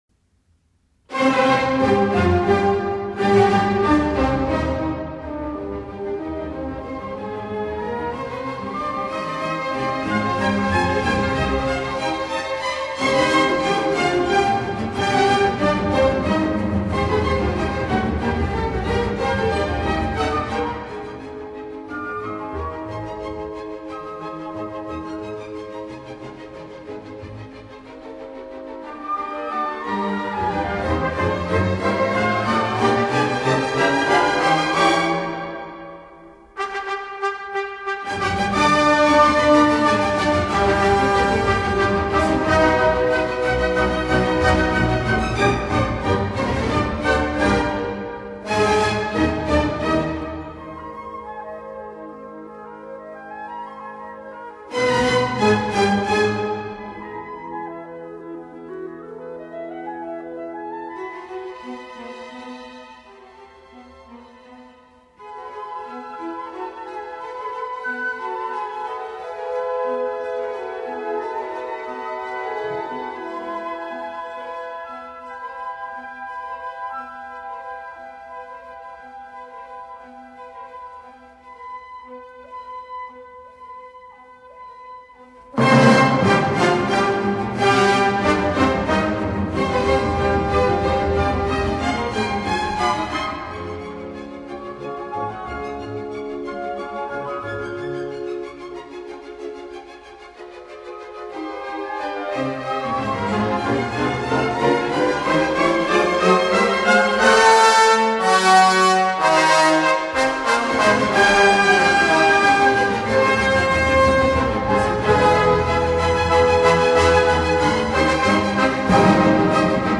Scherzo.